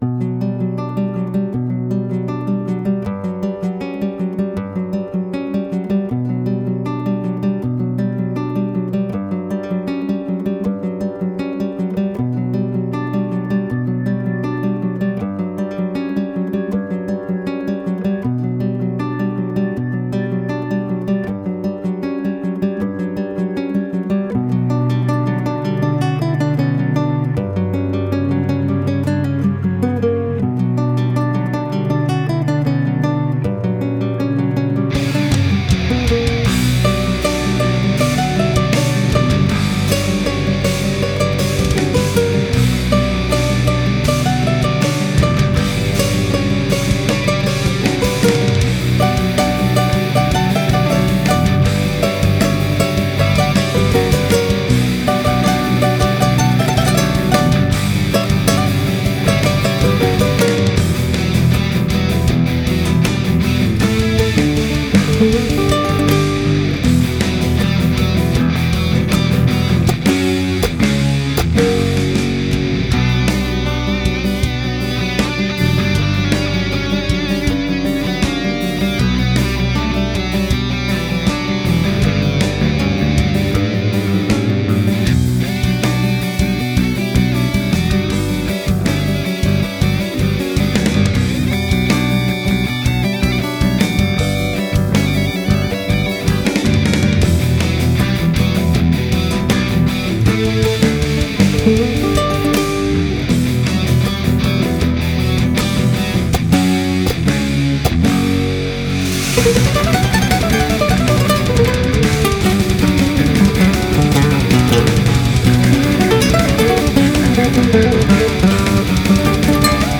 Genre: Guitar Virtuoso, Speed Metal, Flamenco